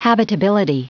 Prononciation du mot habitability en anglais (fichier audio)
Prononciation du mot : habitability